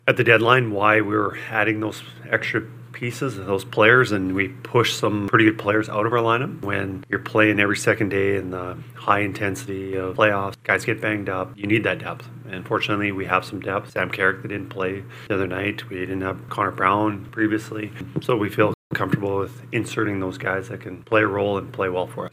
With uncertainty surrounding players like Draisaitl and newcomer Adam Henrique, head coach Kris Knoblauch couldn’t provide many details besides both guys being ‘day to day’, but did mention the importance of having depth, especially during this time of the year.